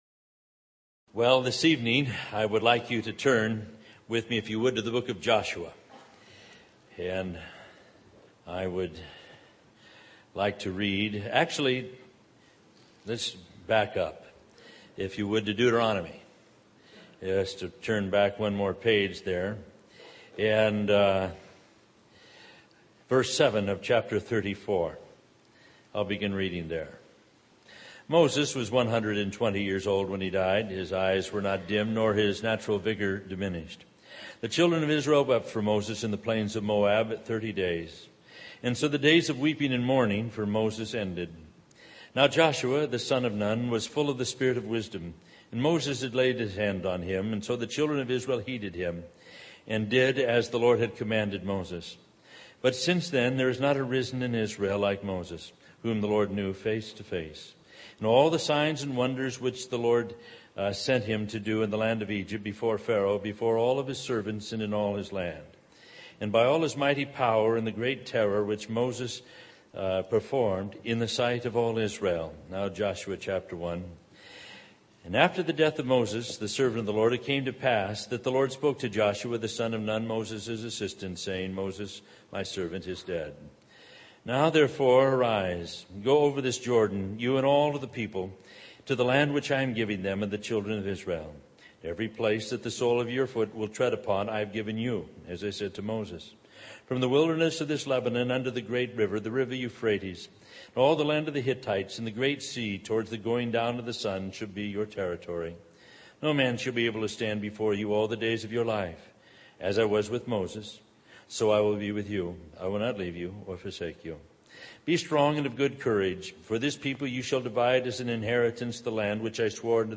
In this sermon, the preacher emphasizes the importance of finding God and experiencing His power and blessings. He highlights the need for guidance and leadership in our lives, especially in times of hopelessness and sorrow.